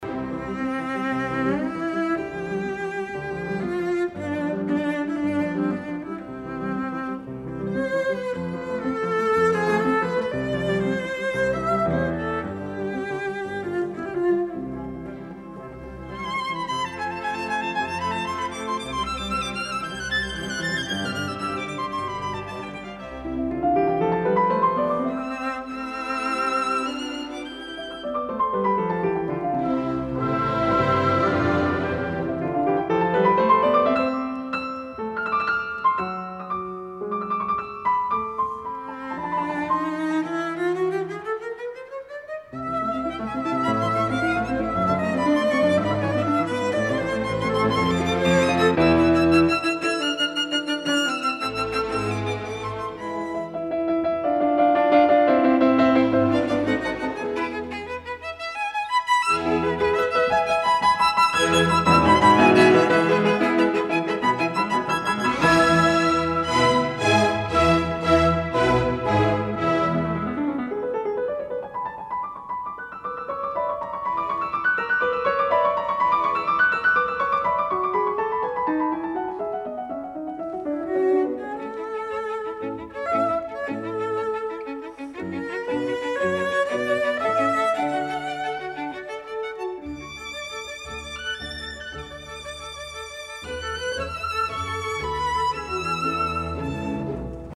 Audiophile Pressing new remastering
The sound has come up marvellously.